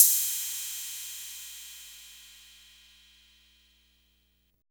808 CRASH.wav